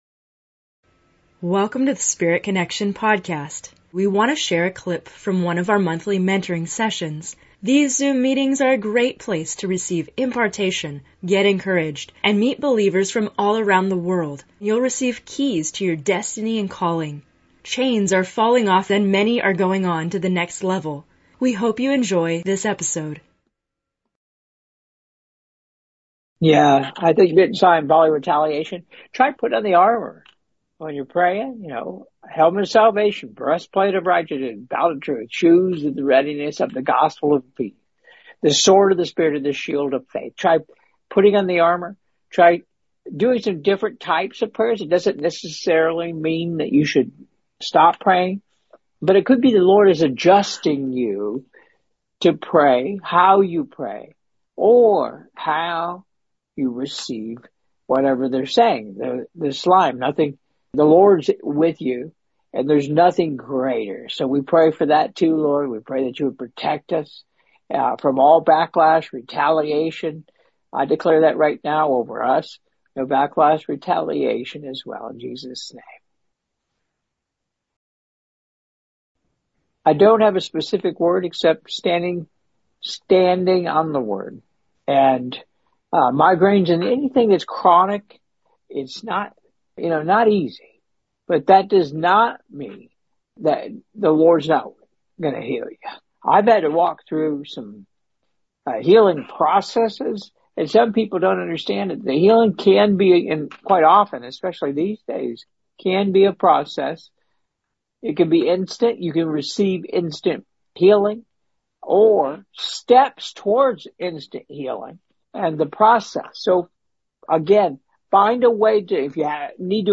In this episode of Spirit Connection, we have a special excerpt from a Q&A session in a recent Monthly Mentoring Session.